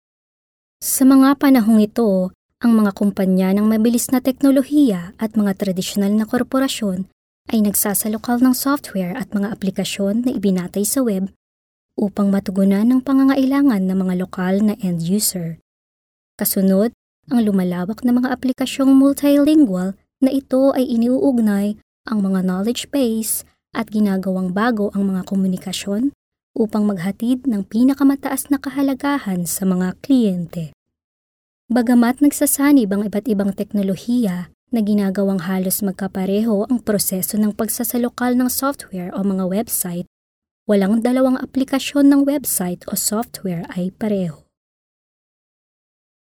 Tagalog voiceover